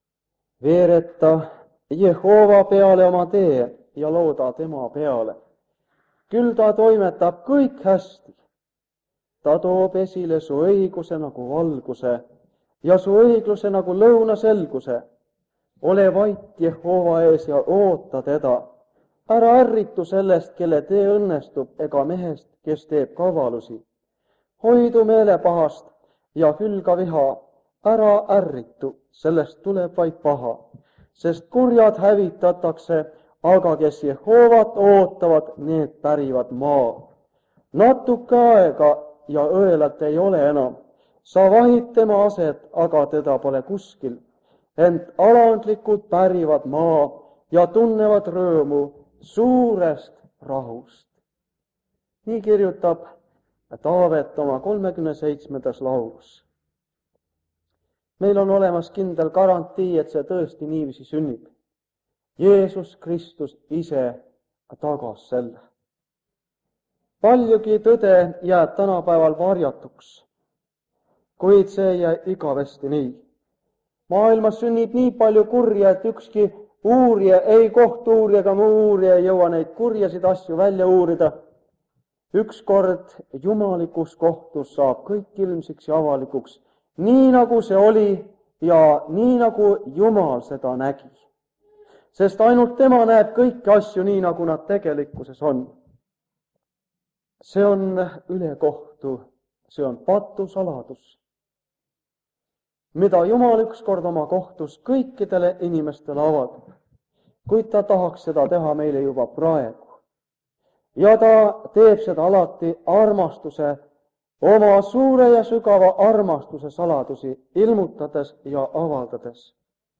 Ilmutuse raamatu seeriakoosolekud Kingissepa linna adventkoguduses
Jutlused